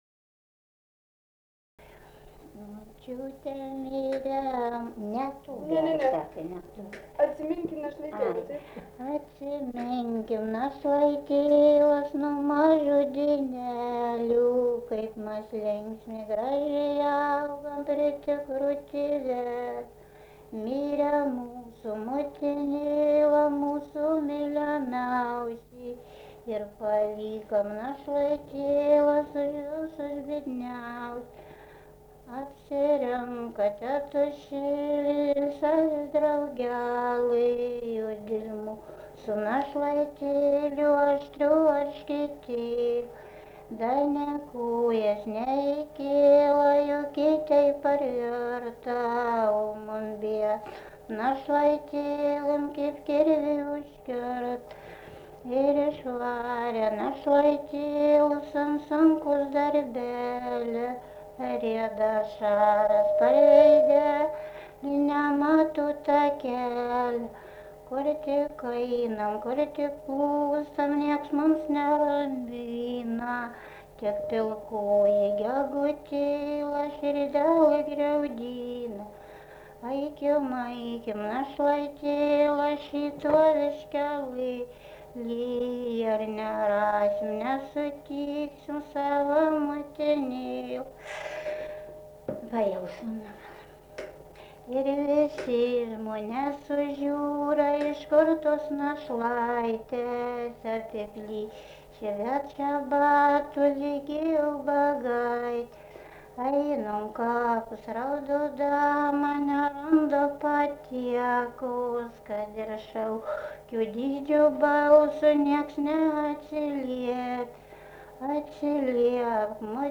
daina
Sereikoniai
vokalinis